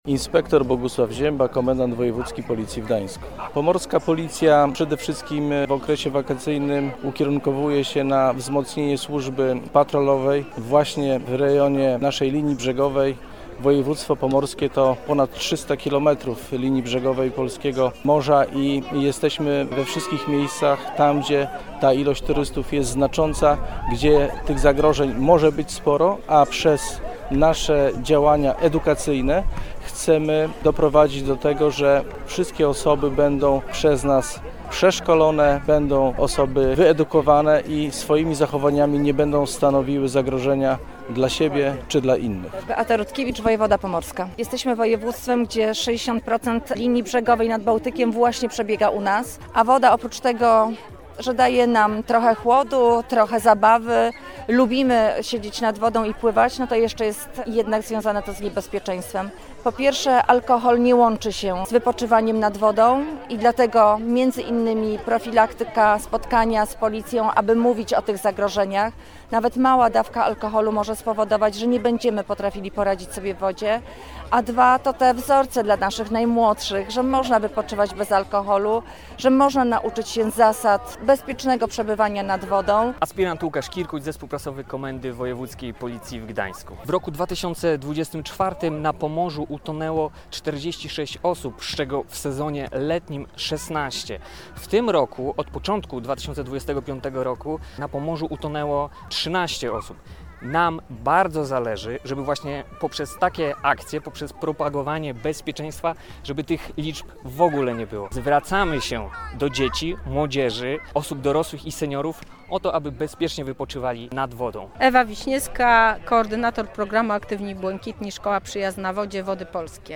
Inspektor Bogusław Ziemba, komendant wojewódzki policji w Gdańsku, chce, aby turyści na Pomorzu mieli udany wypoczynek.